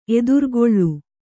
♪ edurgoḷḷu